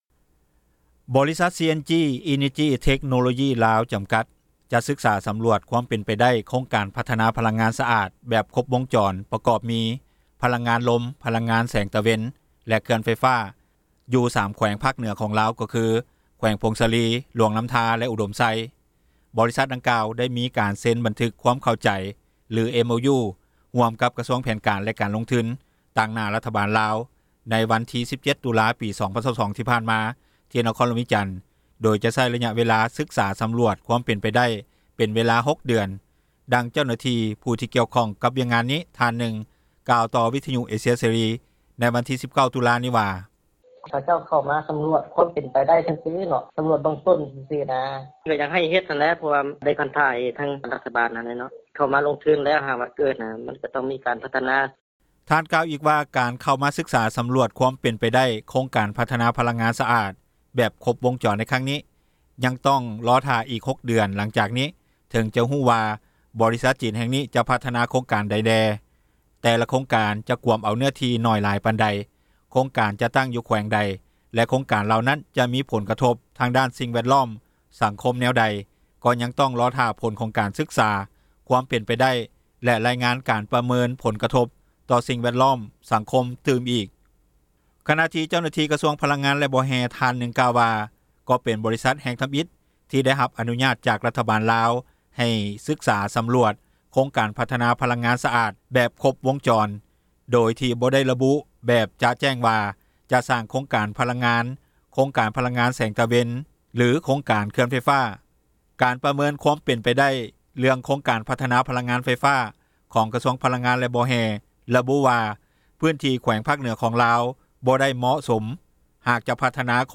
ດັ່ງເຈົ້າໜ້າທີ່ ຜູ້ກ່ຽວຂ້ອງ ກັບວຽກງານນີ້ ທ່ານນຶ່ງກ່າວຕໍ່ວິທຍຸ ເອເຊັຽເສຣີ ໃນວັນທີ 19 ຕຸລານີ້ວ່າ:
ດັ່ງຊາວບ້ານ ໄດ້ກ່າວຕໍ່ວິທຍຸ ເອເຊັຽເສຣີ ໃນມື້ດຽວກັນນີ້ວ່າ: